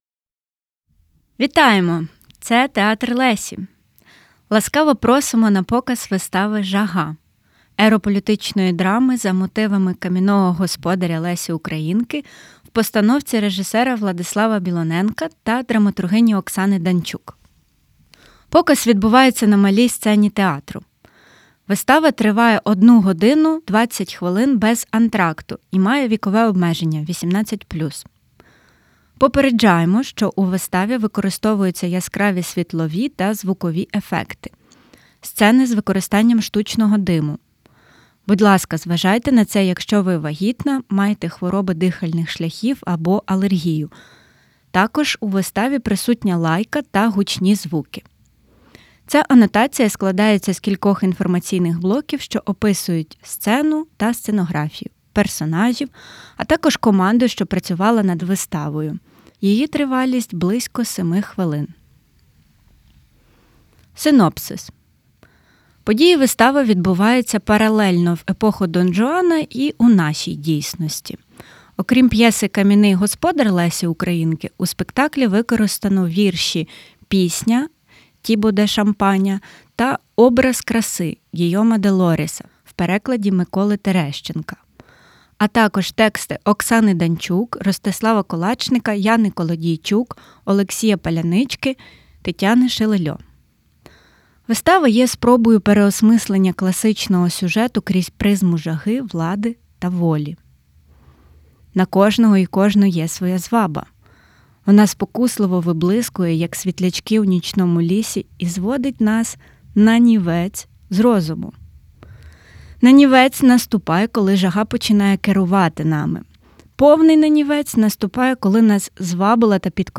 Аудіоанотація вистави "Жага" На кожного і кожну є своя зваба.